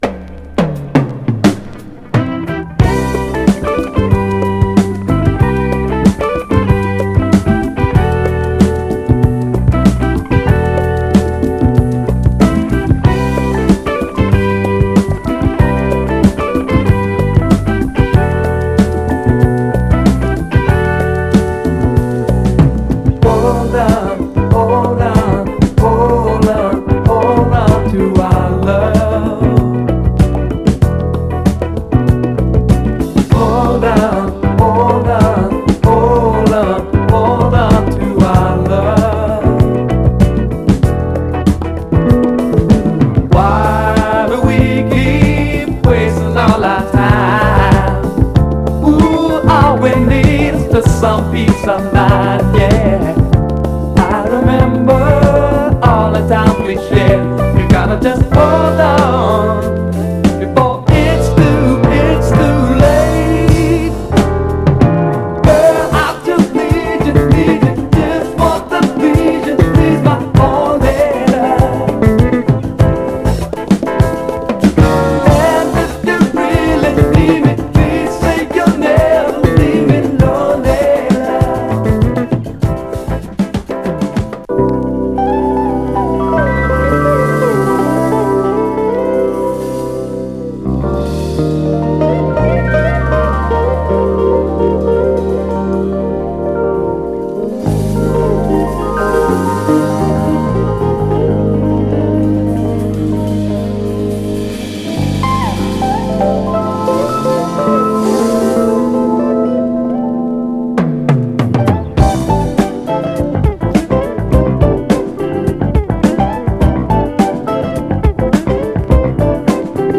ただし音への影響は少なくプレイ概ね良好です。
※試聴音源は実際にお送りする商品から録音したものです※